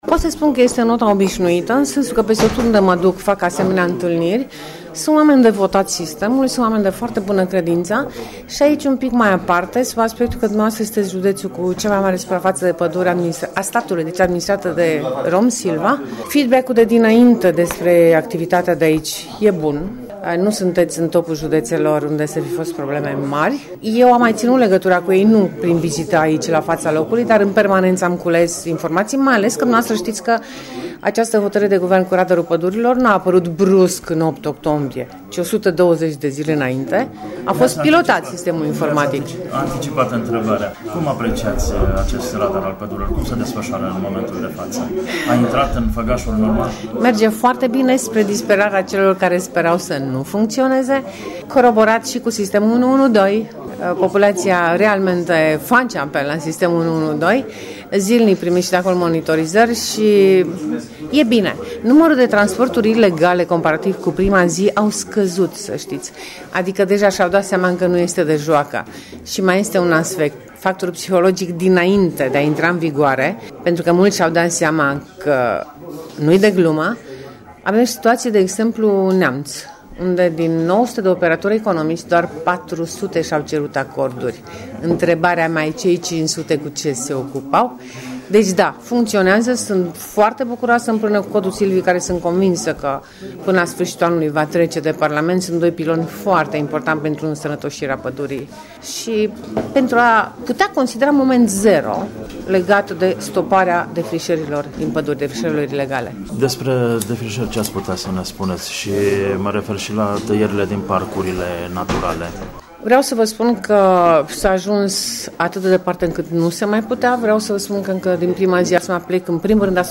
Referitor la întâlnirea cu silvicultorii, radurul pădurilor şi tăierile ilegale, ministrul Doina Pană a declarat în exclusivitate pentru Radio România Reşiţa: